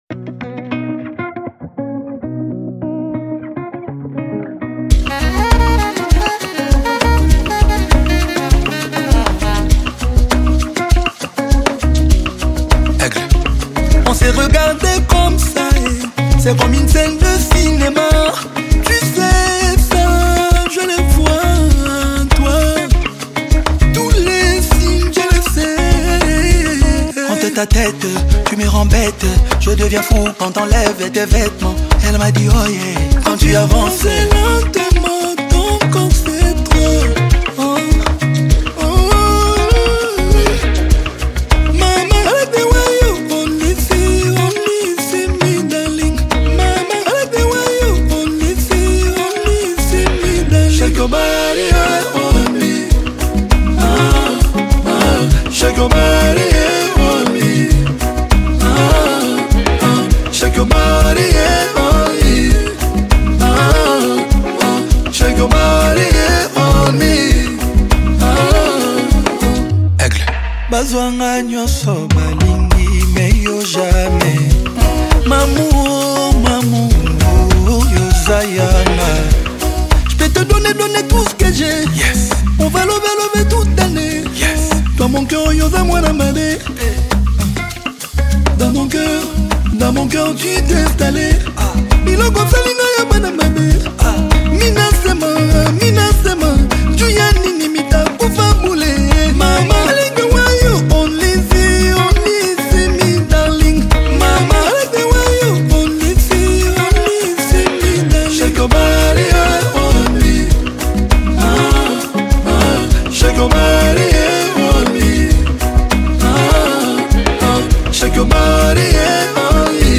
Congolese Rumba/Afro-Pop single
Rhumba